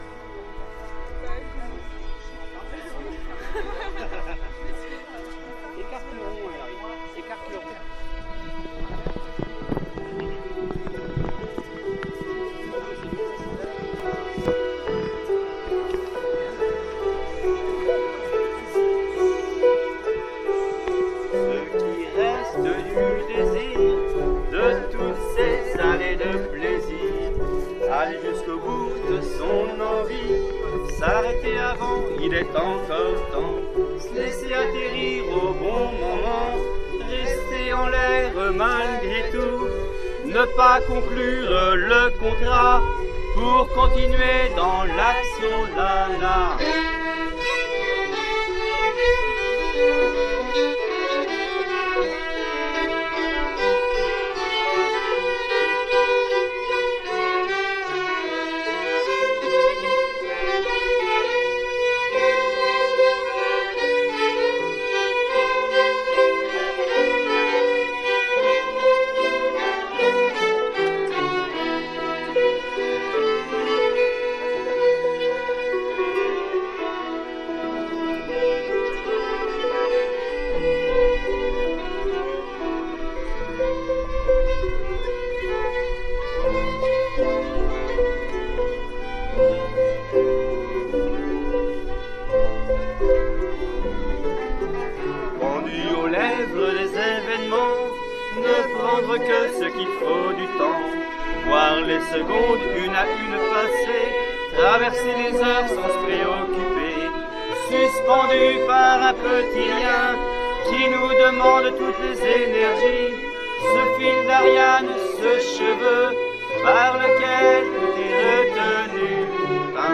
02_ce_qui_reste_du_desir_harpe_violon.mp3